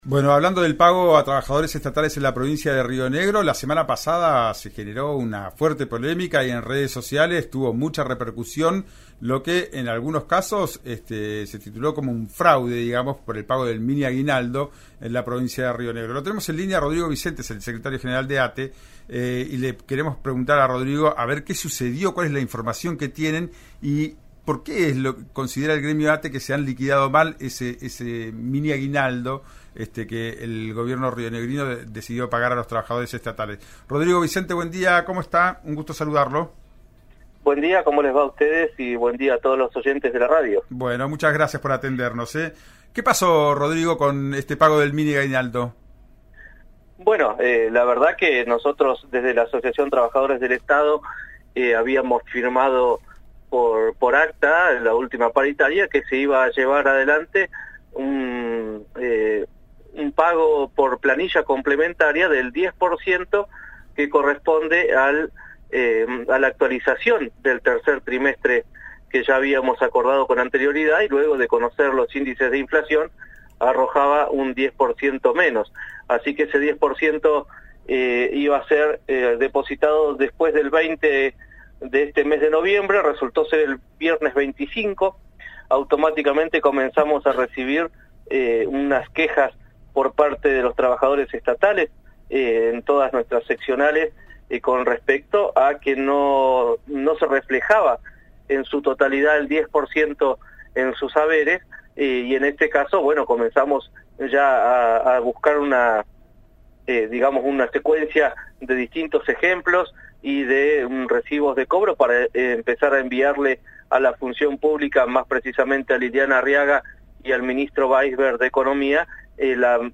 en «Ya es tiempo» por RÍO NEGRO RADIO: